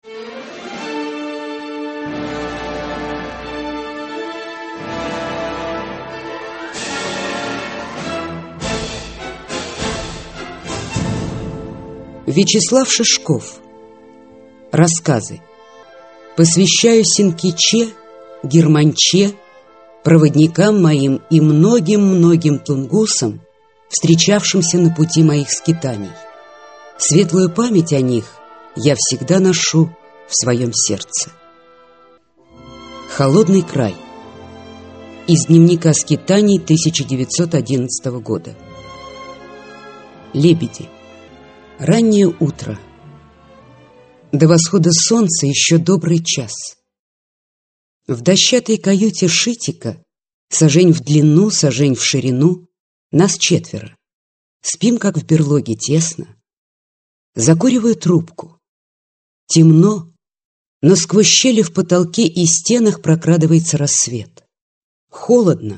Аудиокнига Алые сугробы (сборник) | Библиотека аудиокниг